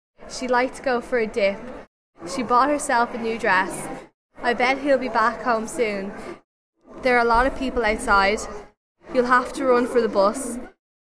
It is very obvious that the DRESS vowel is lowered, that the BACK vowel is retracted from [æ] to almost [ɑ] and that the DIP vowel is slightly lowered.
DIP DRESS BACK LOT BUS (advanced speaker)
DIP_DRESS_BACK_LOT_BUS_(advanced_speaker)_F_20.mp3